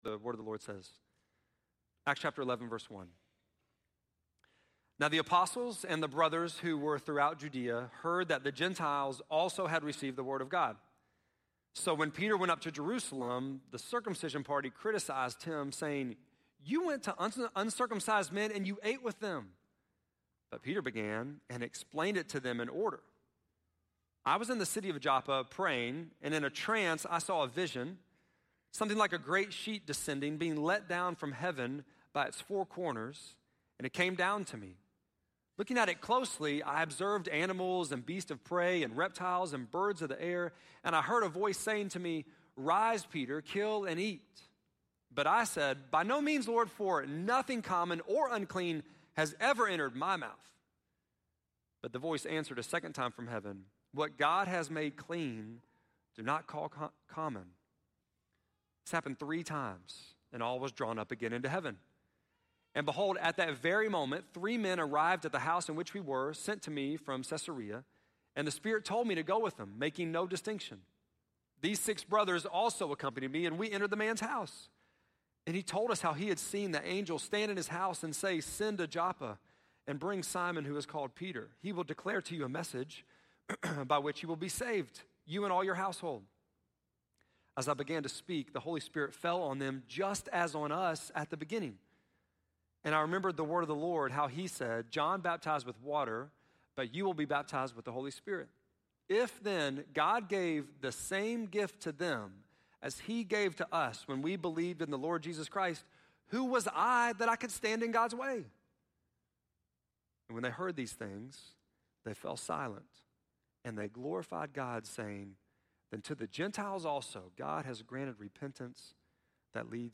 8.18-sermon.mp3